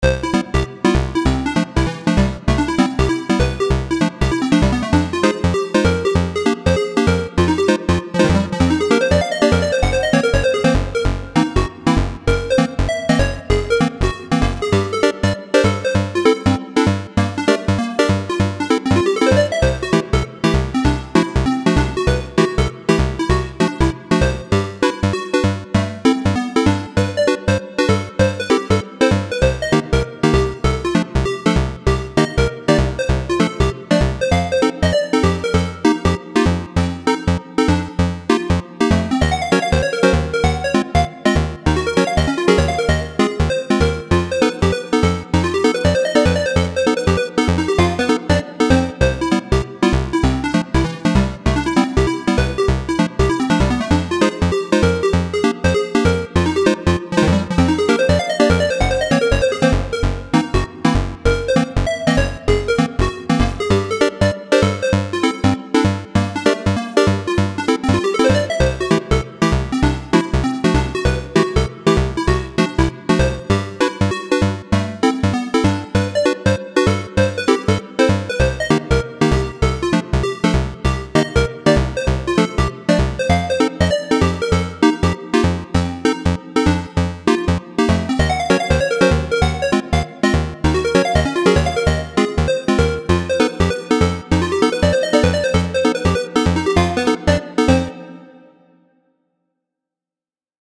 Frozen In Time (Chiptune)